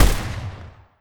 AR2_Shoot 08.wav